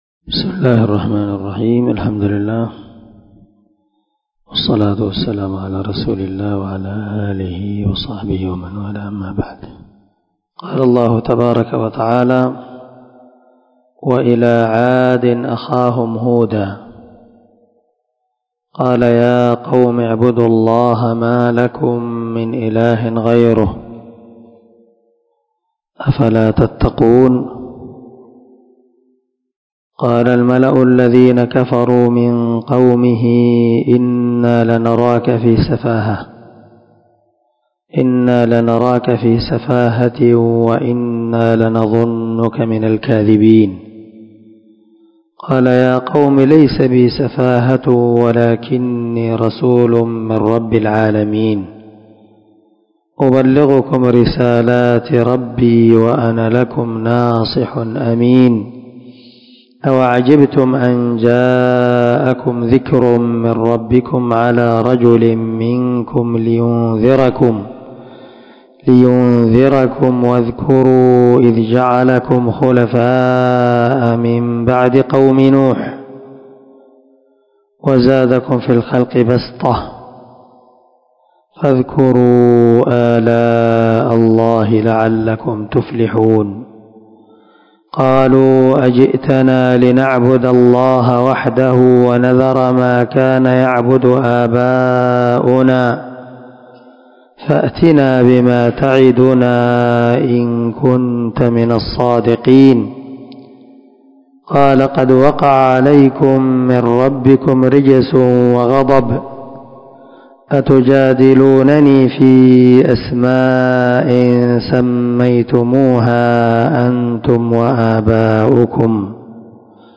470الدرس 22 تفسير آية ( 65 – 72 ) من سورة الأعراف من تفسير القران الكريم مع قراءة لتفسير السعدي